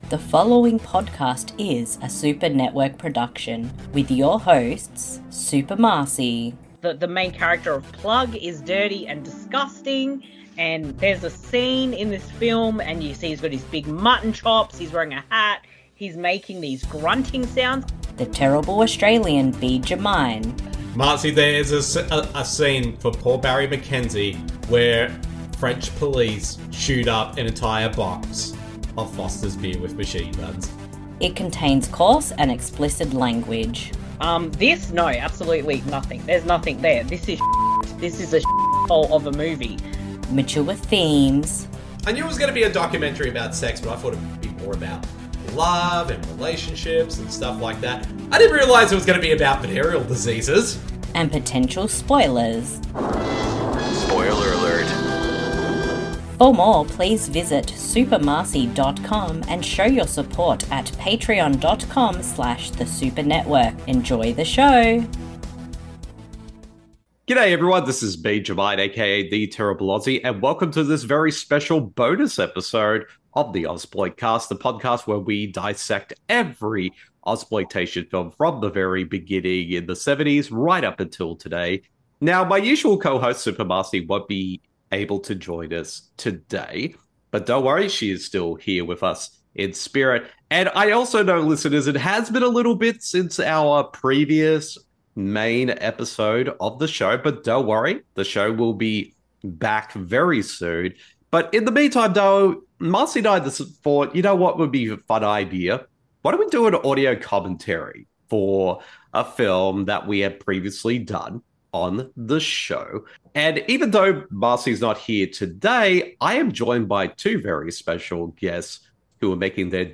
The Ozploit Cast Bonus Episode Razorback (1984) Audio Commentary